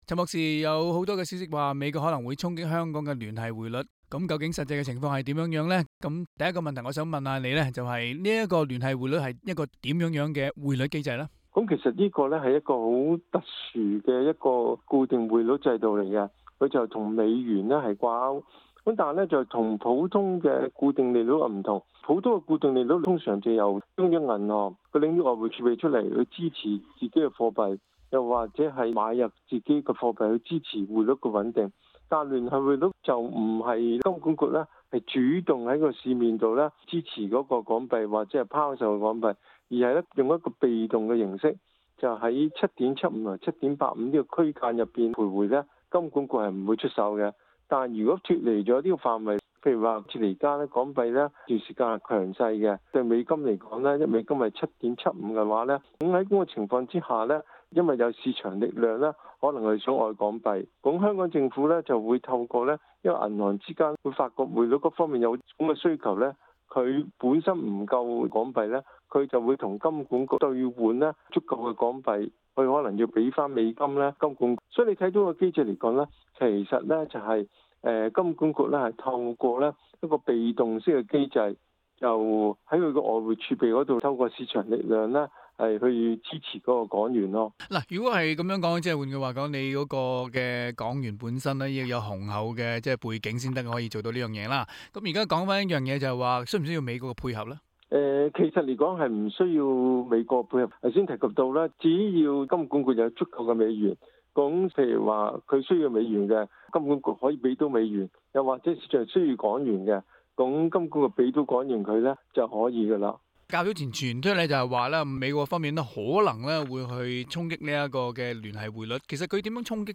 请大家收听今期的访问。）